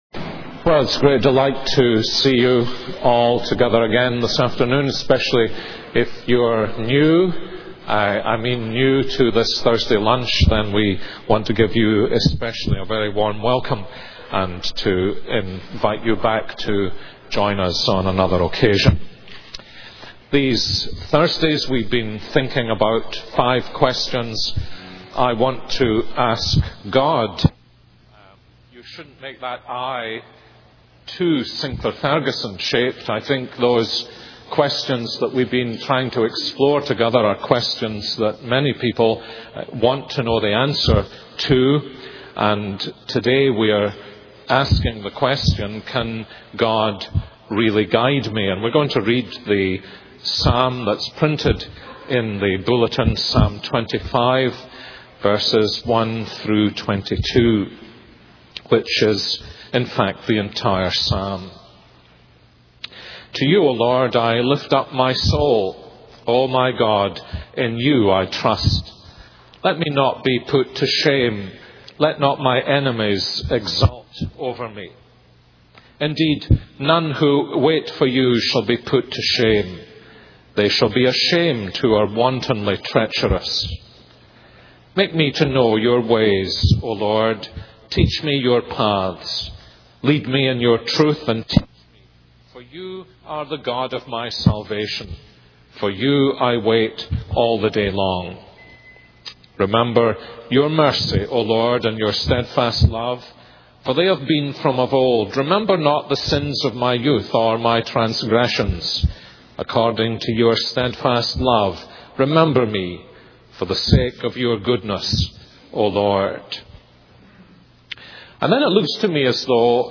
This is a sermon on Psalm 25.